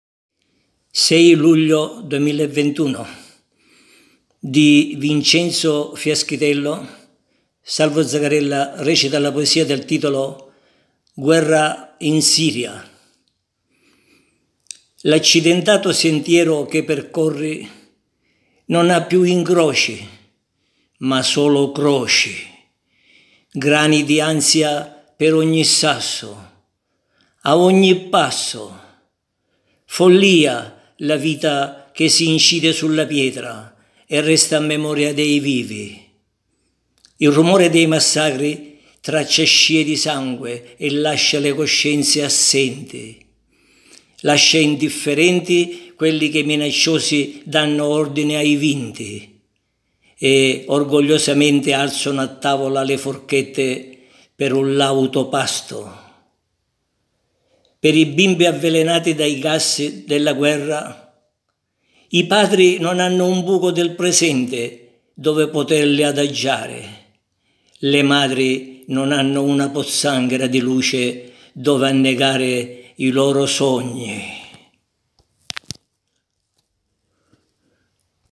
interpreta la poesia ''Guerra in Siria'' di Vincenzo Fiaschitello